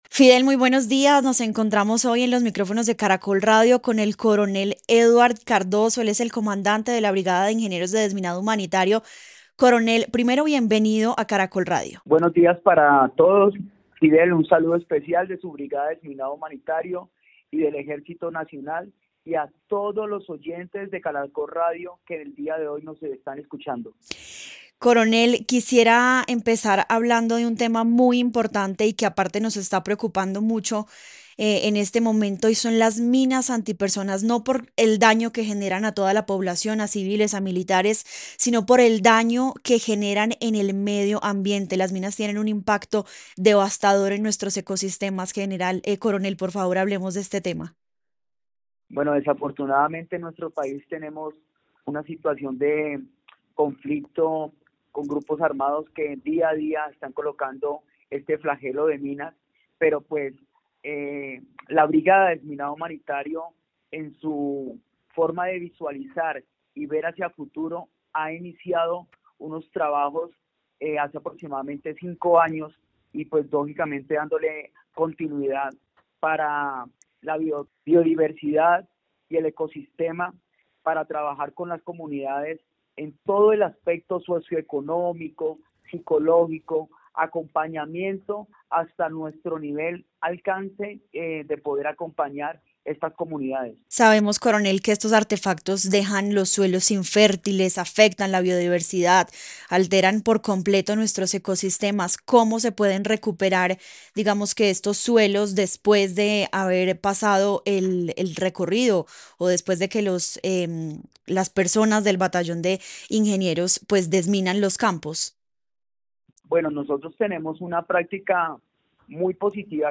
En entrevista con Caracol Radio